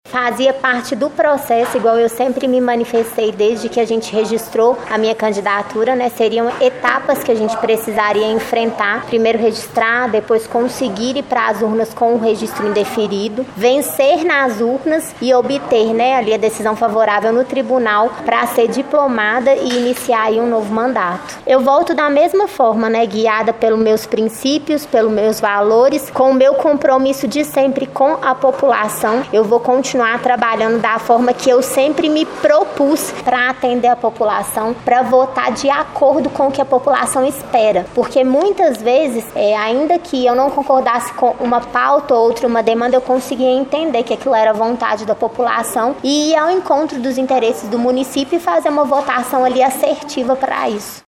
Márcia acompanhou todo o procedimento no Cartório Eleitoral e comemorou. Ela falou sobre o processo, desde o registro de sua candidatura até a liminar do TJMG, que garantiu a ela a diplomação. A vereadora eleita também falou sobre seu retorno à Câmara e suas expectativas para atuação no legislativo.